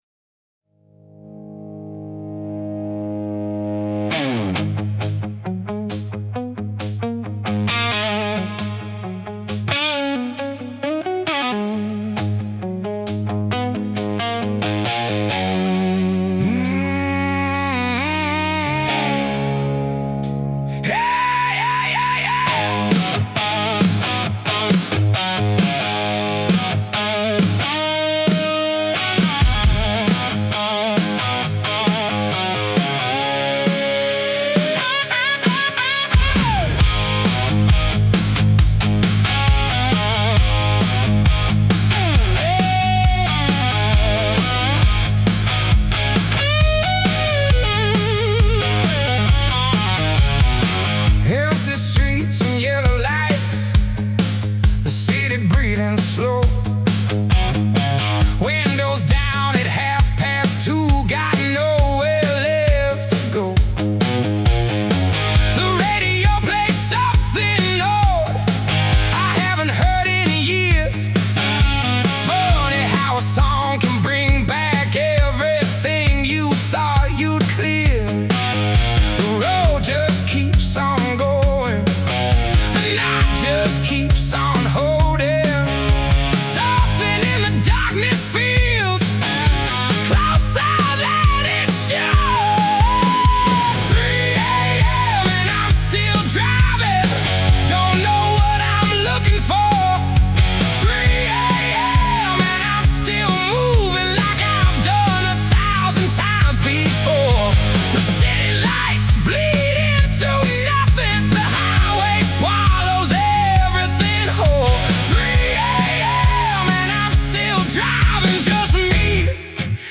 hard rock, blues rock, hypnotic rock